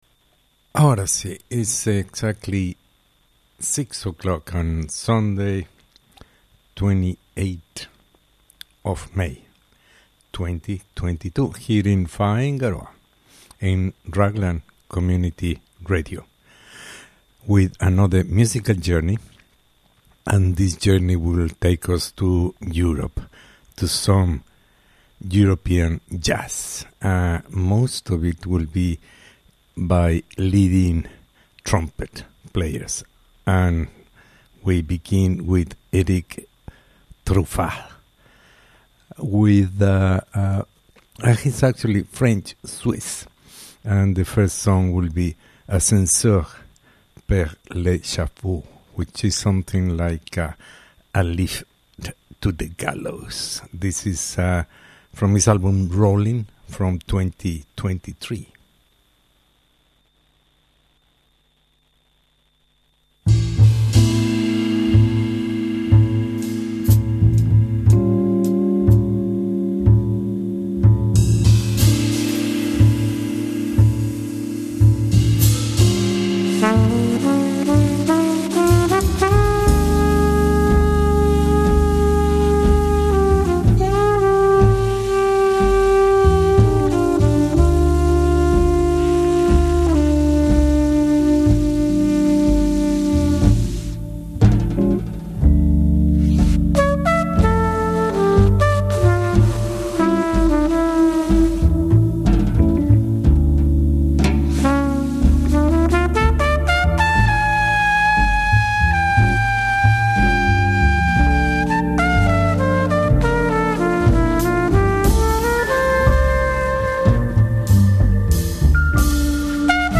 Jazz trumpet from Europe.